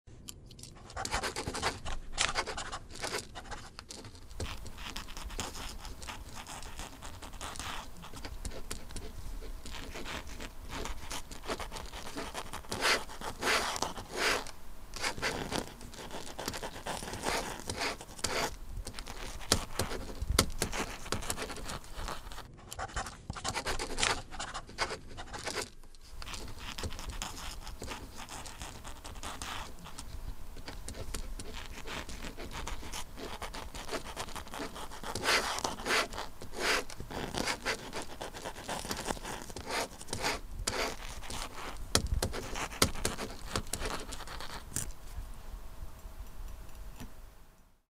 Здесь вы найдете скрип гусиного пера, шелест чернильных штрихов и металлического пера на бумаге.
Шорох пера по плотной бумаге